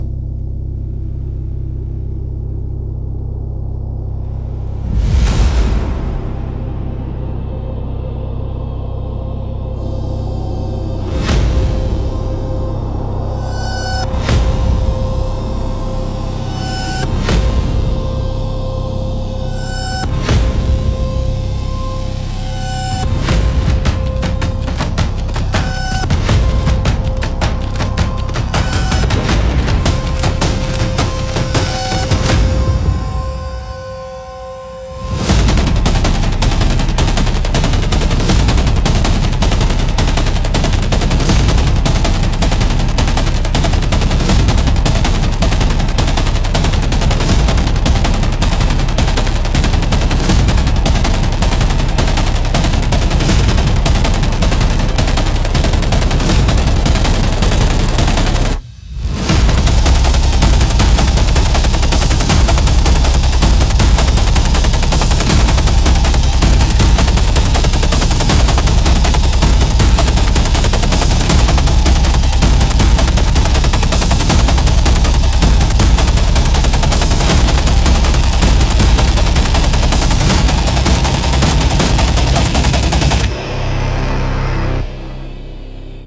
Instrumental - The Machine Speaks in Rhythm -1.28